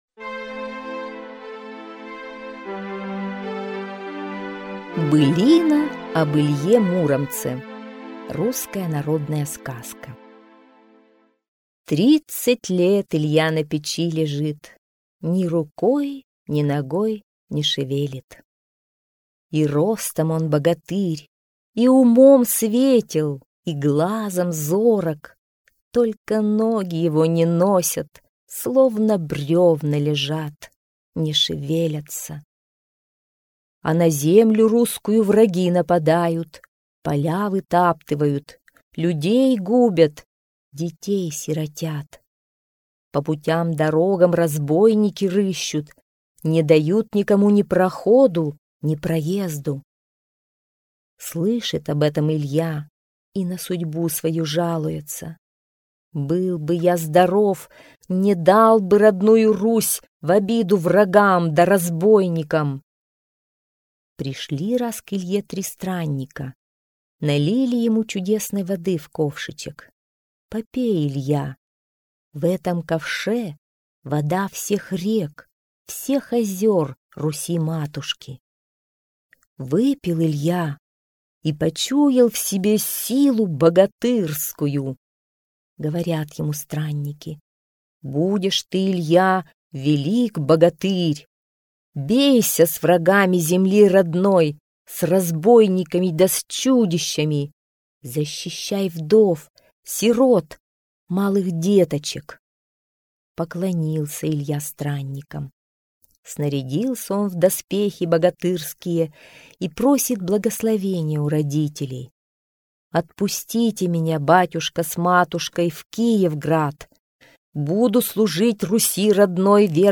Былина об Илье Муромце – русская народная аудиосказка